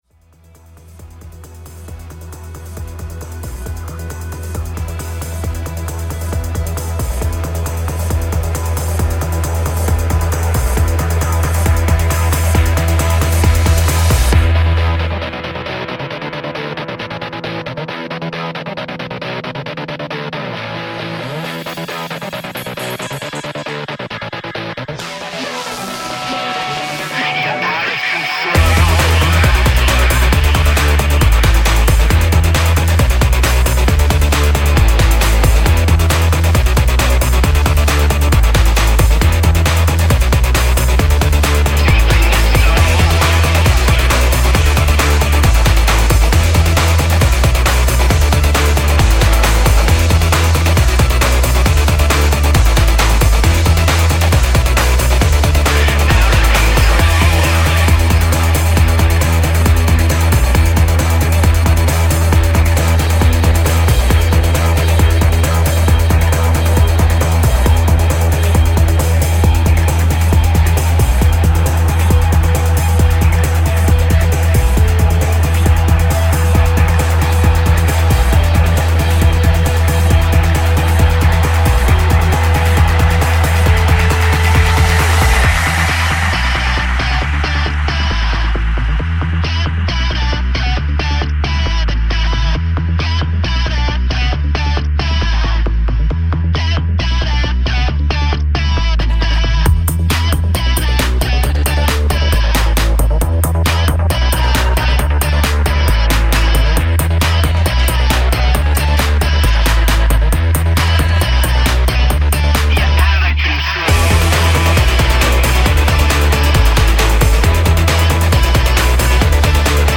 BPM135
Audio QualityPerfect (High Quality)
The crescendo in the middle cannot be stopped!